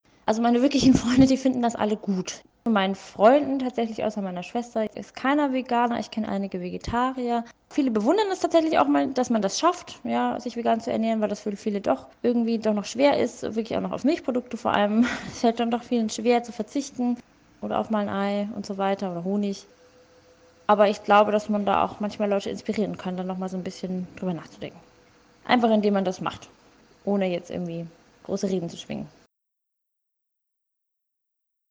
Hörverstehen